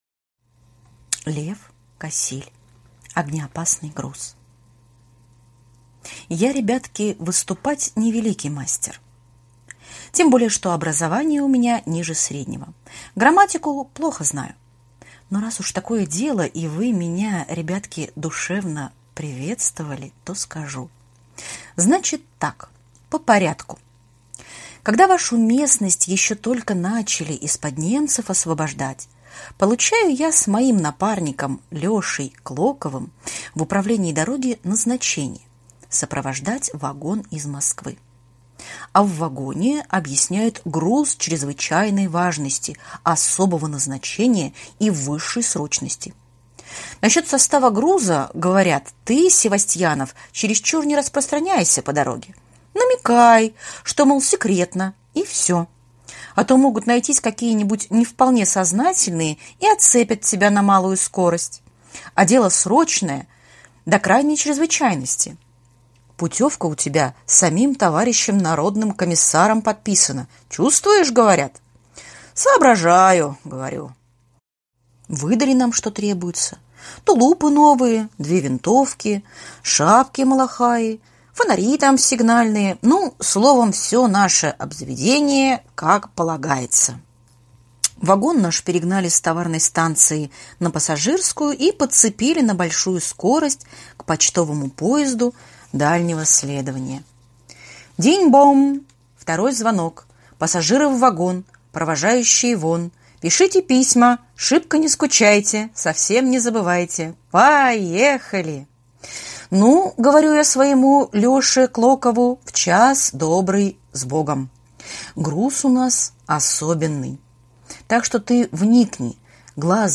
Огнеопасный груз - аудио рассказ Кассиля - слушать онлайн